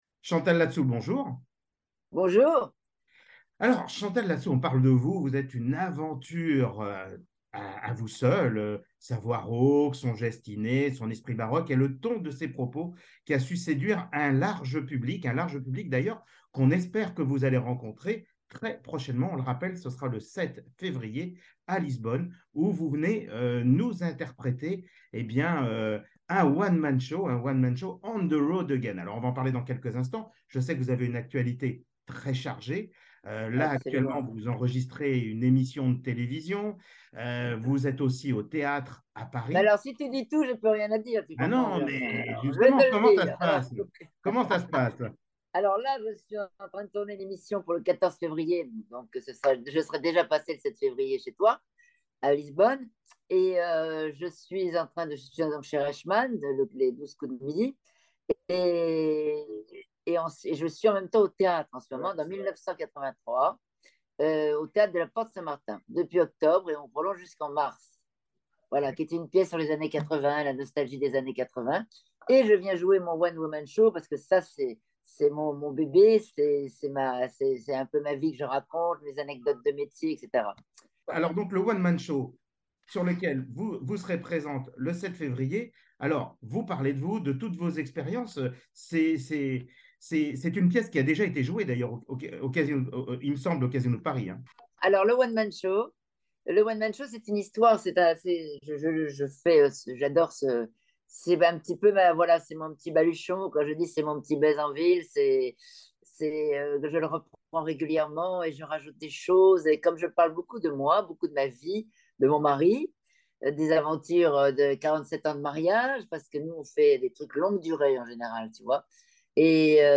Chantal Ladesou en interview sur la French Radio avant son spectacle à Lisbonne
Interviewée dans les loges pour son enregistrement à l'emission de Jean Luc Reichmann elle nous fait vivre ce que sera son show et son plaisir de rencontrer les Français de Lisbonne.
Sa voix rauque, son geste innée, son esprit baroque et le ton de ses propos ont su séduire un large public, elle qui sait si bien manier avec brio l’autodérision.